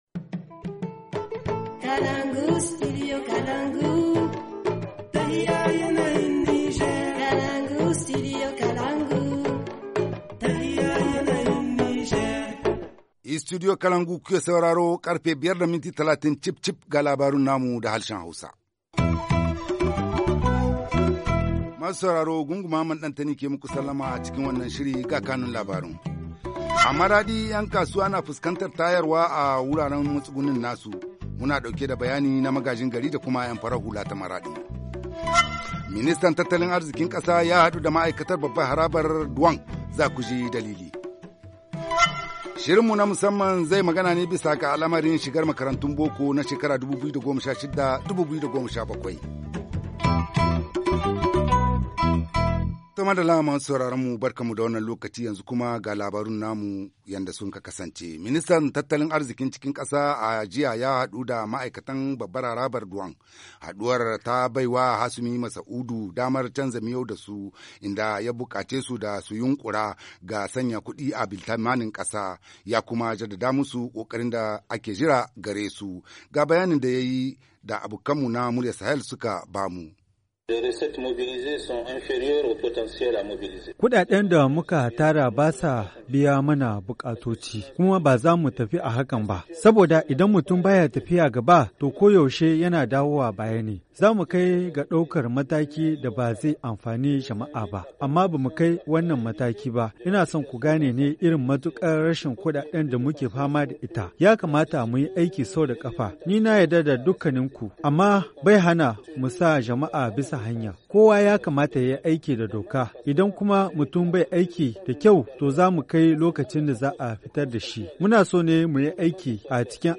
1. Depuis 6heures du matin aujourd’hui, le marché de Kadro à Maradi est investi par les forces de défense et de sécurité pour les opérations de déguerpissement. Les explications du maire central de la ville, suivies de l’avis des acteurs de la société civile.